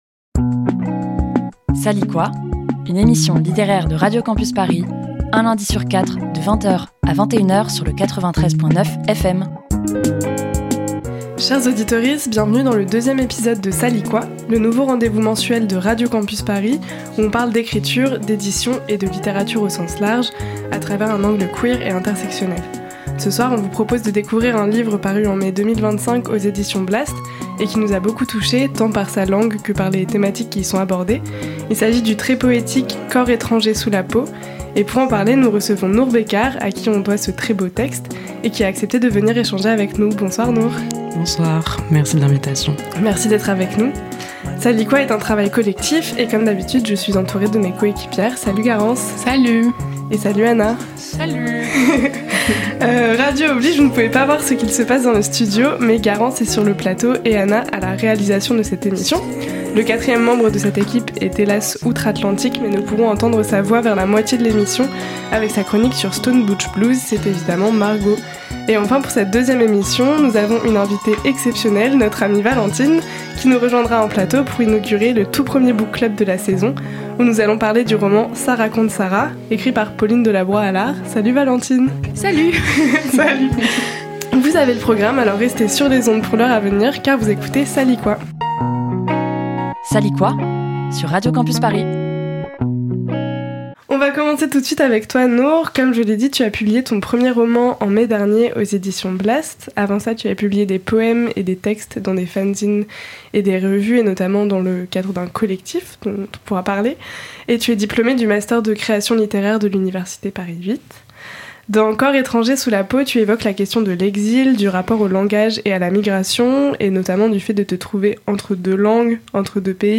Type Magazine Culture